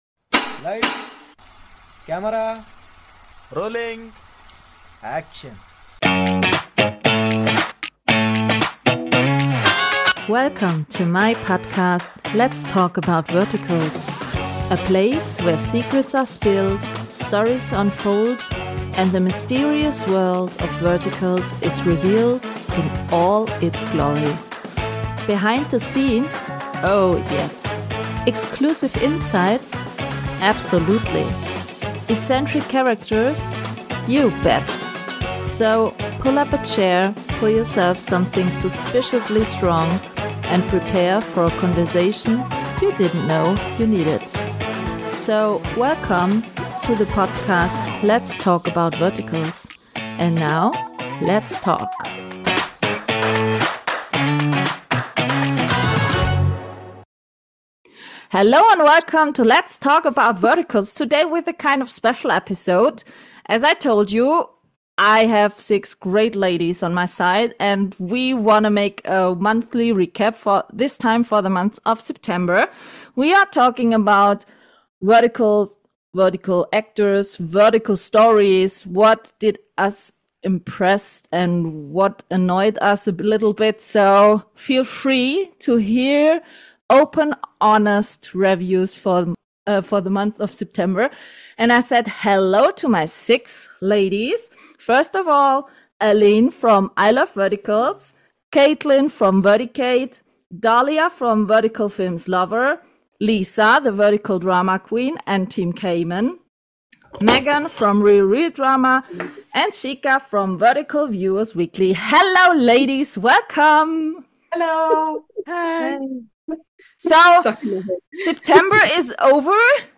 I'll be reviewing the month of September with six ladies who are active in the vertical world.